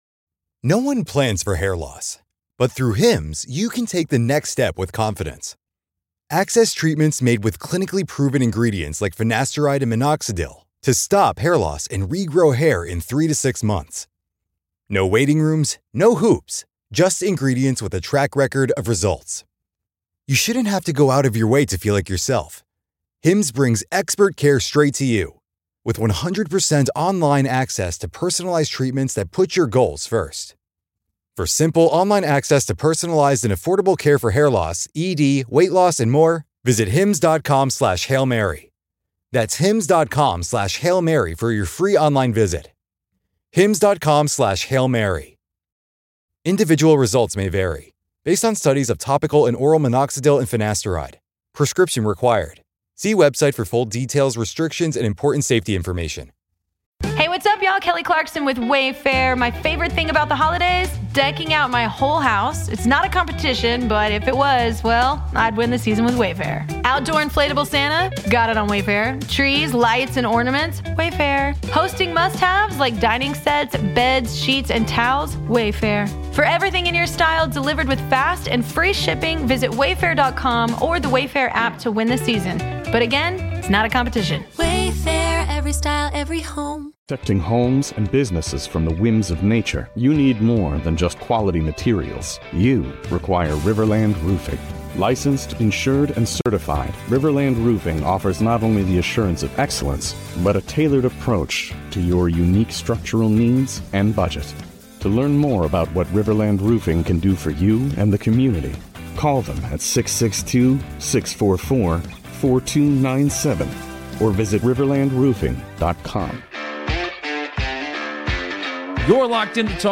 On this LIVE Talk of Champions